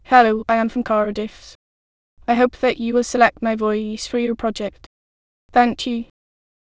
samples/VCTK_p253.wav · voices/VCTK_European_English_Females at 46583eeef89882f8326c93a05c50a25019d6fcc2